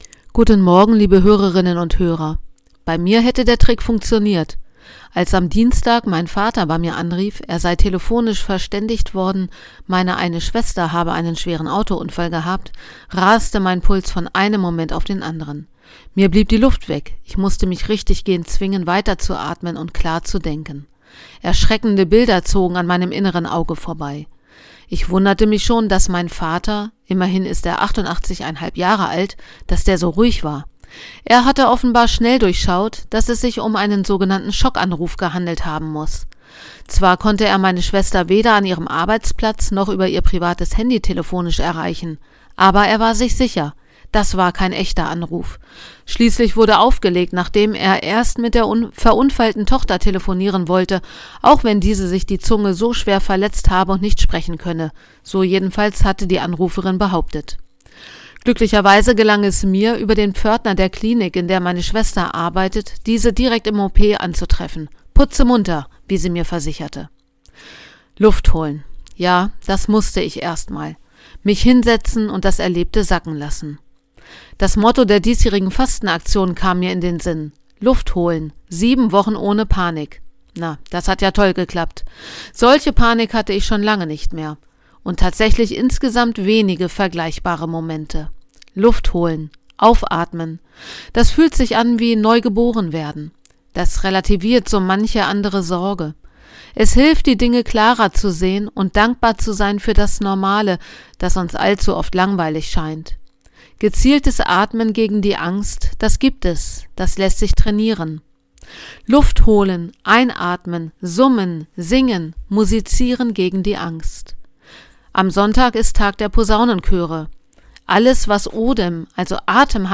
Radioandacht vom 28. März